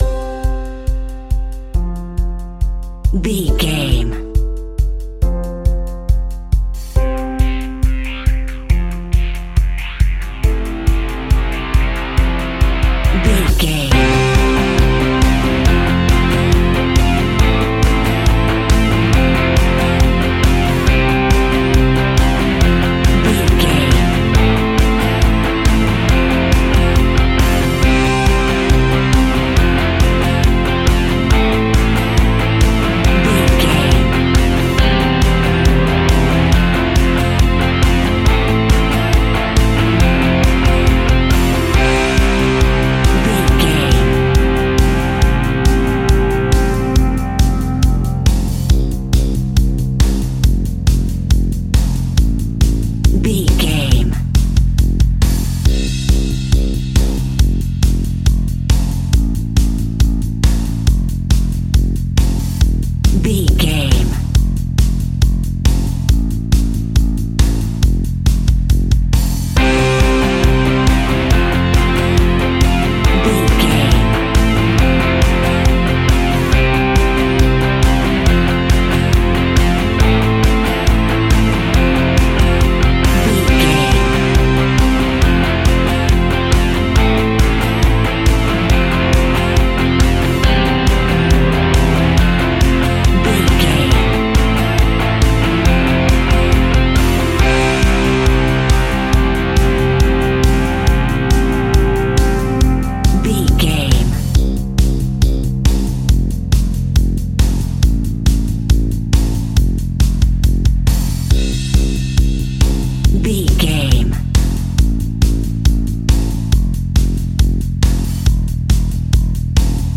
Ionian/Major
energetic
driving
heavy
aggressive
electric guitar
bass guitar
drums
indie pop
uplifting
motivational
instrumentals
piano
organ